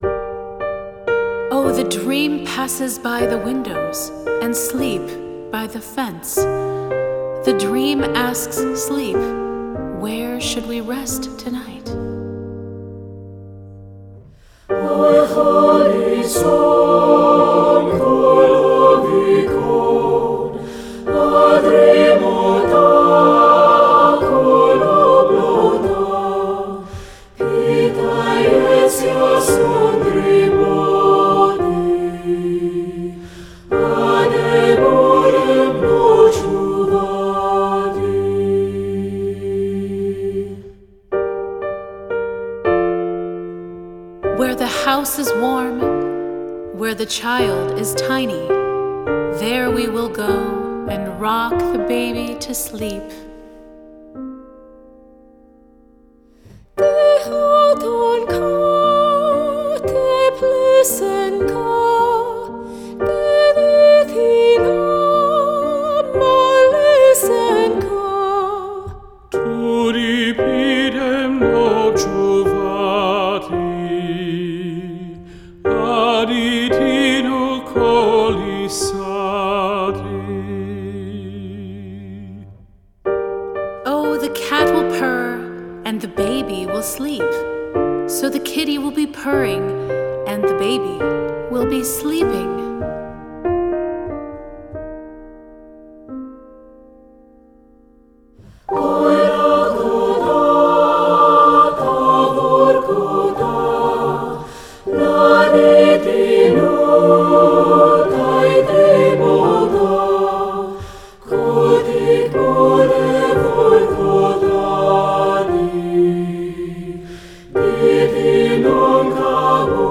Choral Multicultural Women's Chorus
Ukrainian Folk Song
SSA